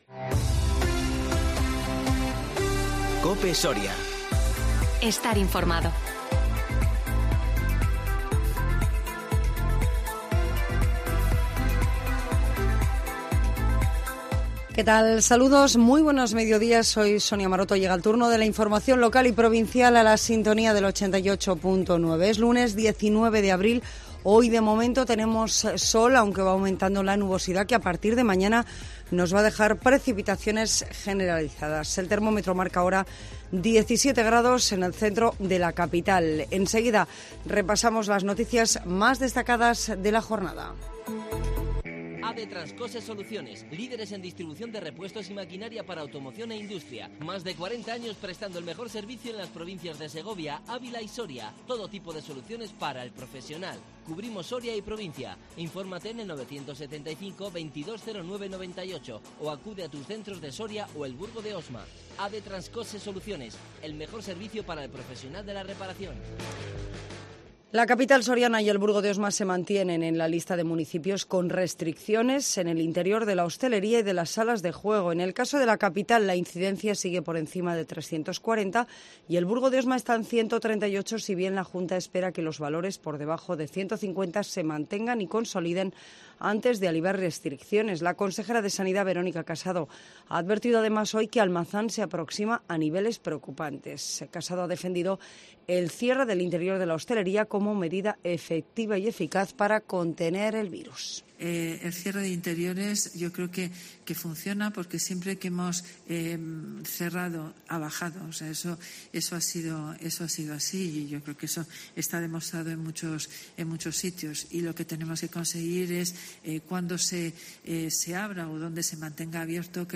Informativo Mediodía 19 abril 2021